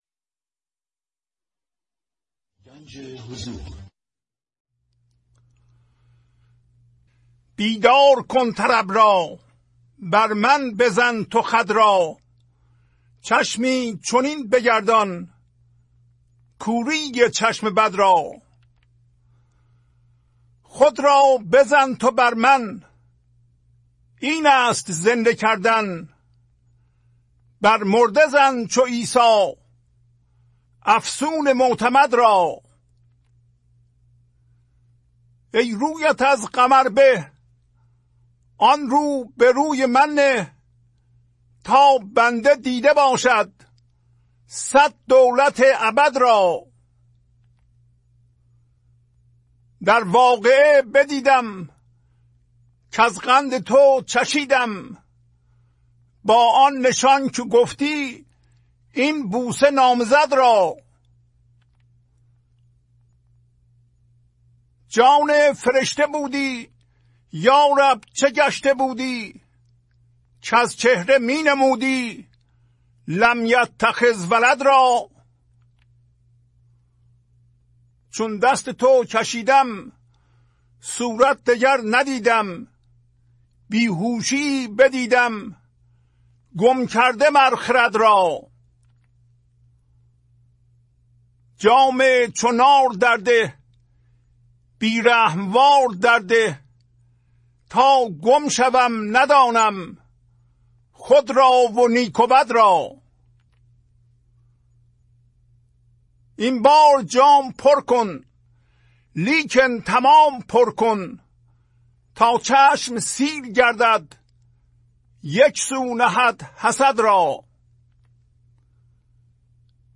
خوانش تمام ابیات این برنامه - فایل صوتی
1008-Poems-Voice.mp3